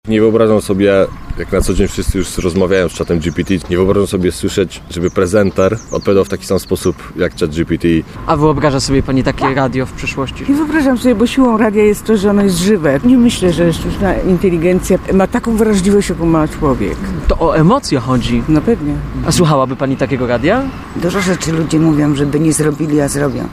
W tym roku najważniejszym tematem jest rola jaką w rozwoju radia może odegrać sztuczna inteligencja. O opinie w tej sprawie zapytaliśmy Poznaniaków.